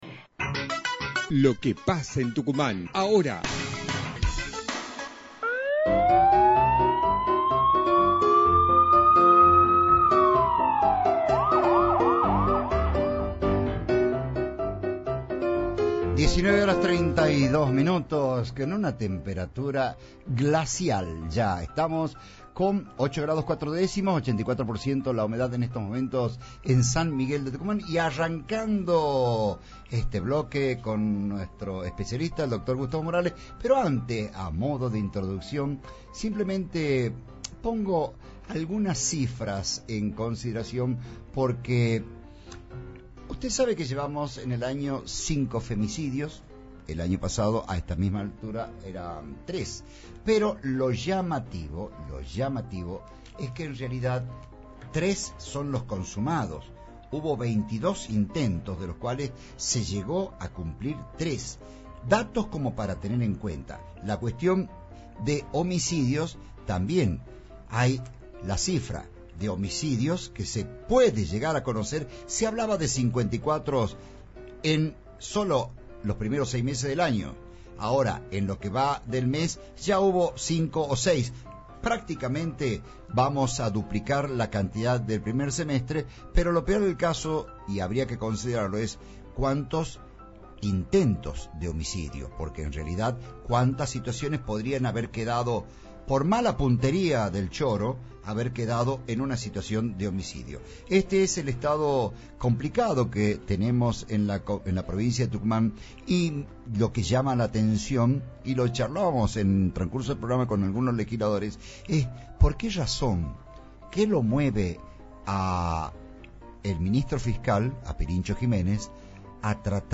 anunció ayer en el Programa AÑOS DE RADIO (Metro 89.1)